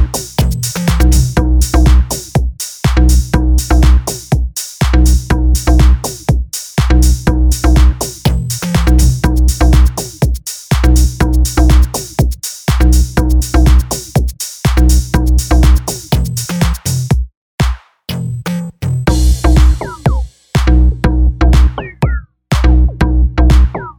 no Backing Vocals Dance 2:33 Buy £1.50